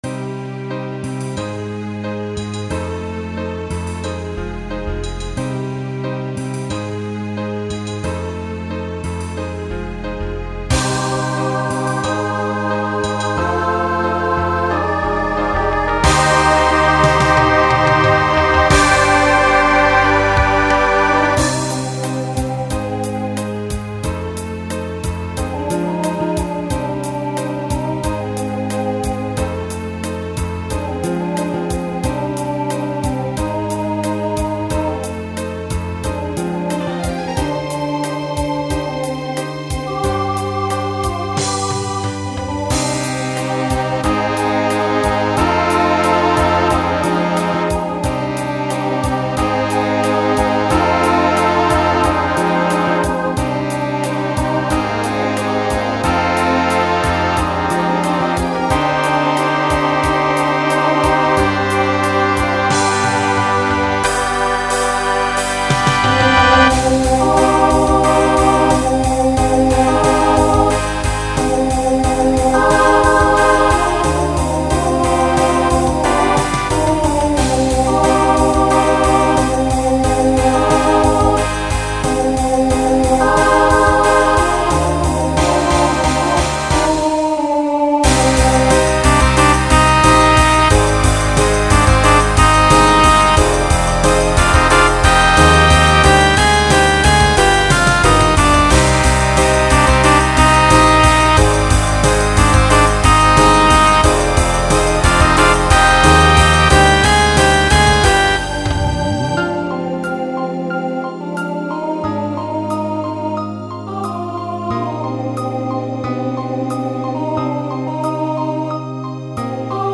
Genre Pop/Dance Instrumental combo
Opener Voicing SSA